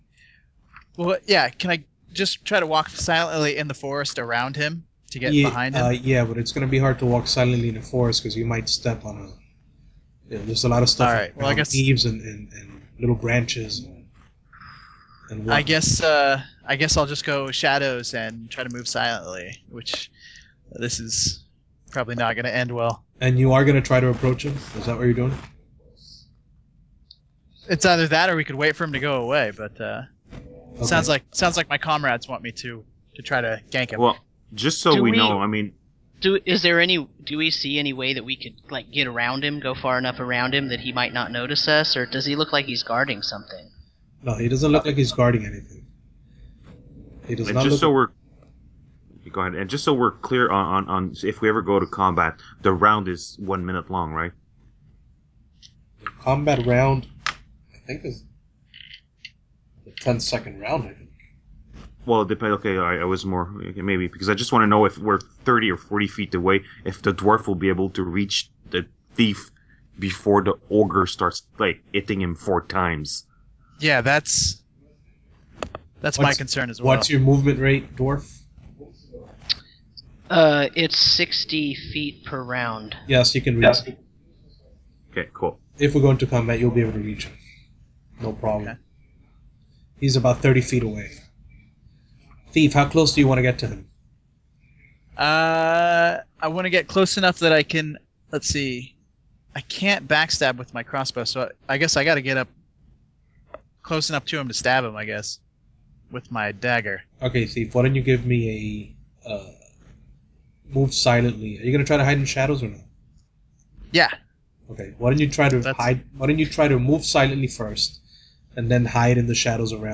Red Box gaming via Skype
I decided to experiment a little bit tonight and play a bit of old school D&D. I ran a third level Basic D&D game over Skype, using a classic module, “Horror on the Hill”. We used the online whiteboard Scriblink as our game table, and rolled real dice (honor system).
redboxactualplay2.mp3